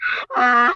Звуки ослов
Голос обычного осла